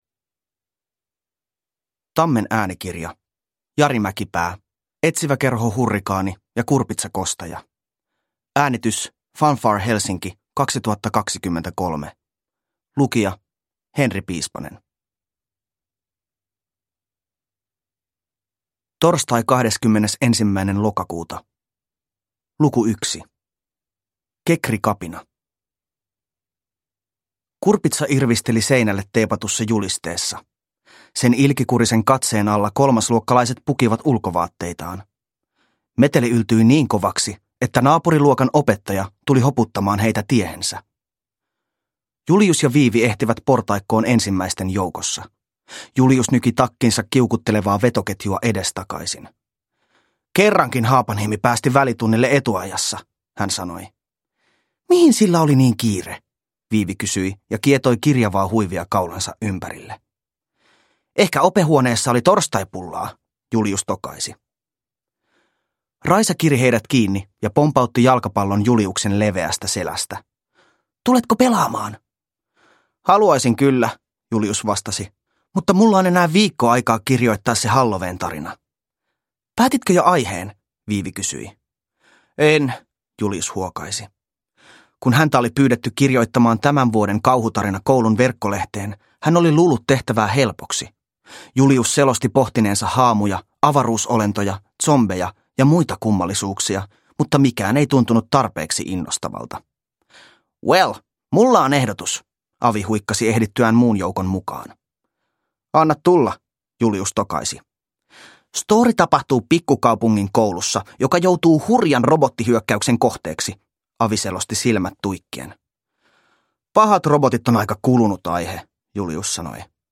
Etsiväkerho Hurrikaani ja kurpitsakostaja – Ljudbok – Laddas ner